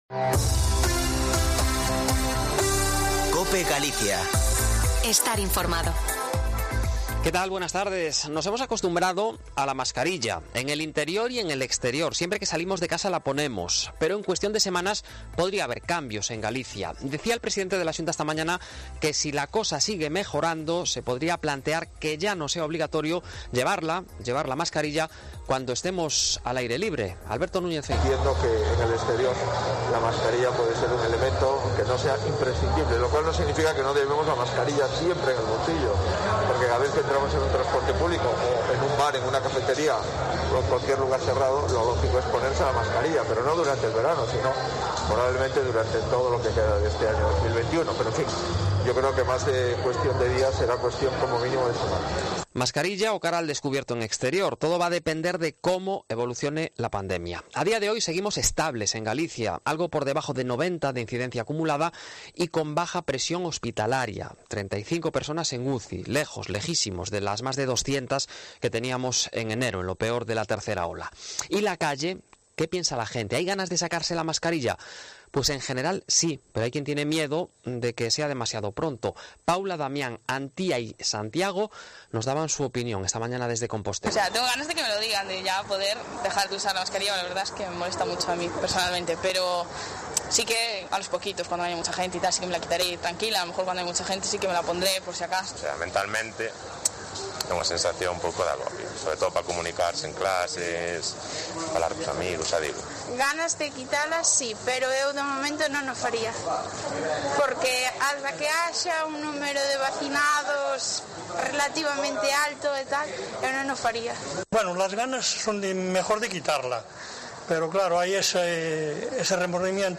Informativo Mediodía Cope Galicia 18/05/2021. De 14.48 a 14.58h